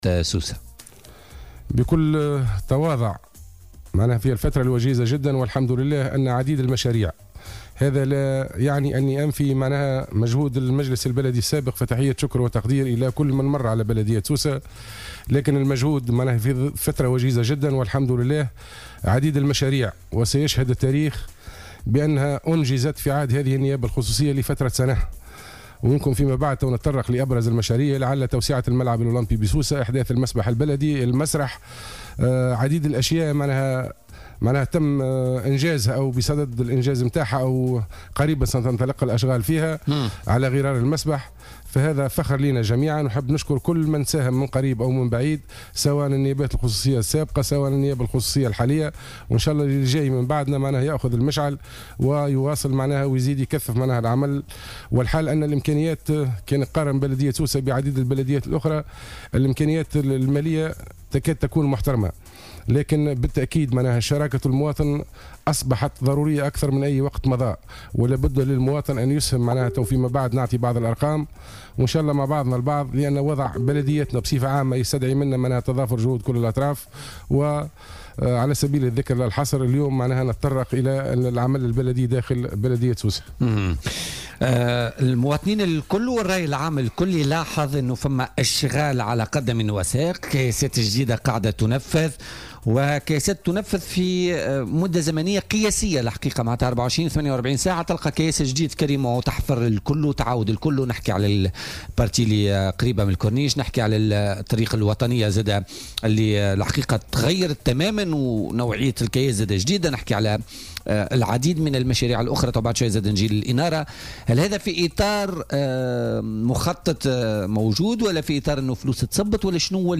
أكد المعتمد الأول ورئيس النيابة الخصوصية بسوسة حسين بوشهوة ضيف بولتيكا اليوم الخميس بلدية سوسة بالتنسيق مع الإدارة الجهوية للتجهيز و وكالة التهذيب والتجديد العمراني رصدت ميزانية تقدر بأكثر من 15 مليار لإنجاز أشغال على مستوى الطرقات ب4 معتمديات تابعة لولاية سوسة.